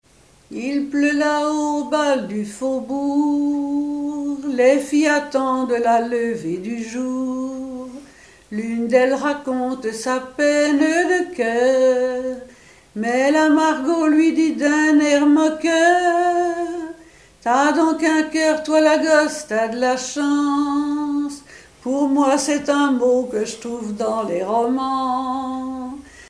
Mémoires et Patrimoines vivants - RaddO est une base de données d'archives iconographiques et sonores.
Genre strophique
Chansons et commentaires
Pièce musicale inédite